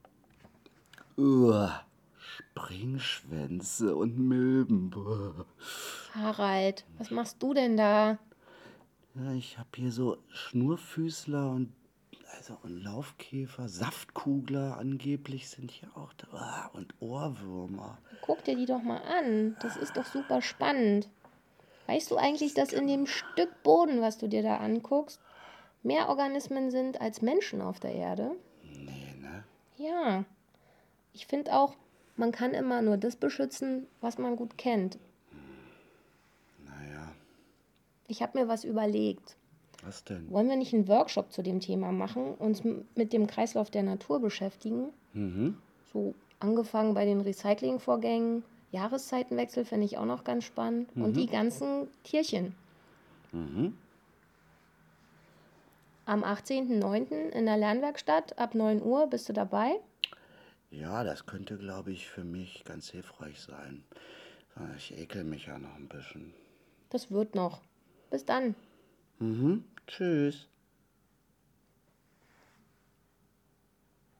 Das Werkstattteam berichtet in kurzen Audiobeiträgen über alles Wissenswerte rund um einzelne Veranstaltungen.